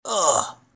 ouch.mp3